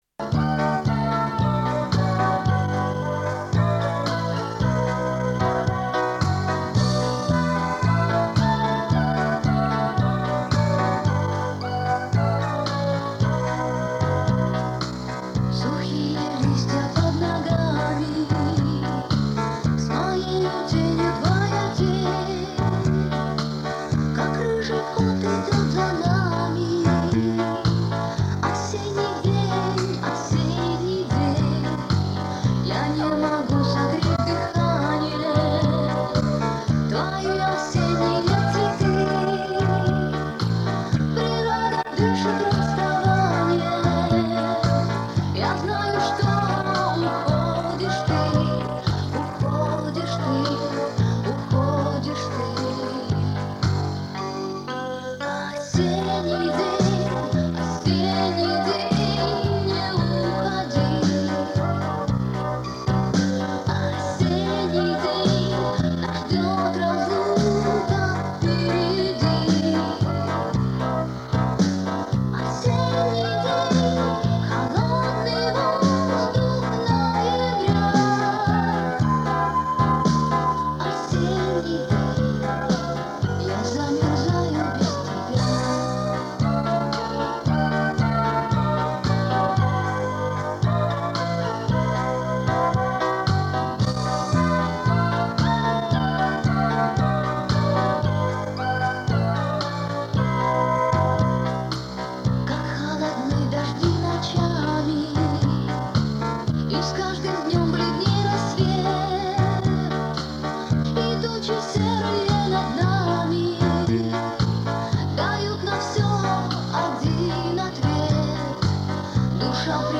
Качество не очень...может у кого найдется лучше?